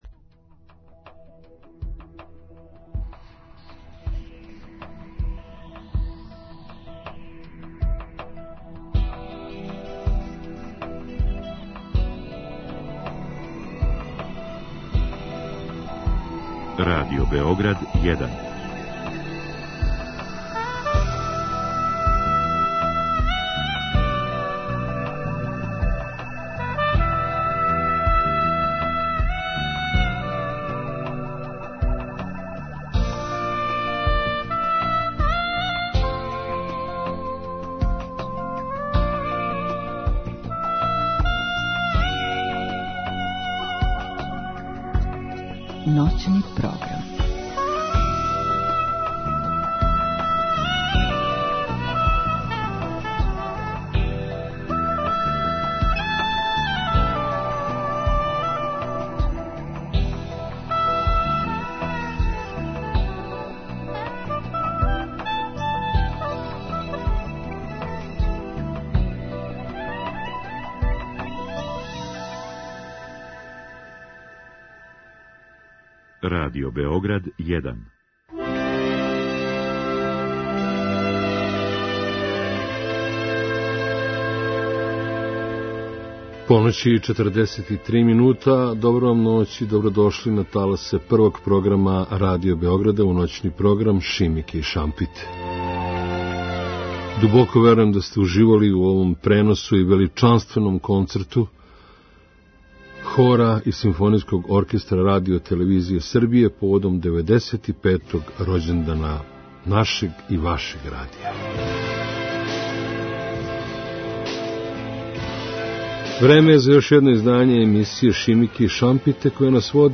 У ноћи суботе на недељу креће још једно издање емисије Шимике и шампите на таласима Првог програма Радио Београда.